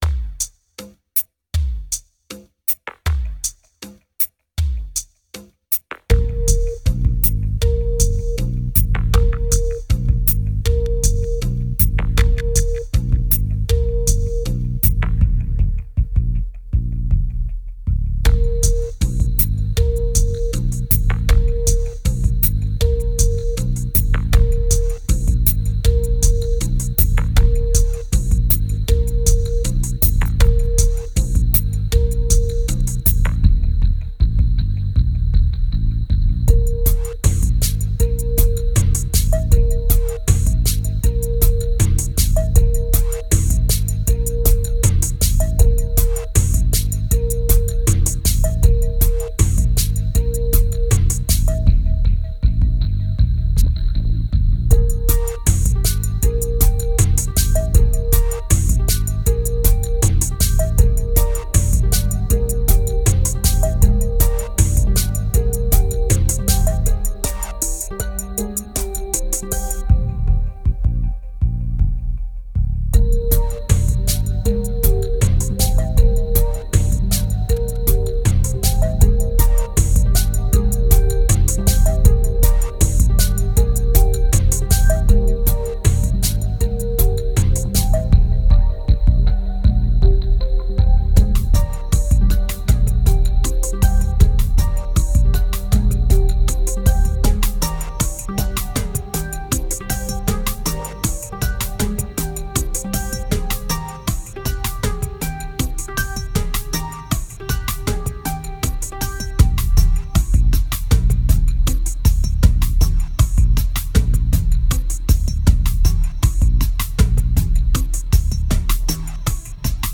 2286📈 - 35%🤔 - 79BPM🔊 - 2009-12-12📅 - -43🌟
Dub Bass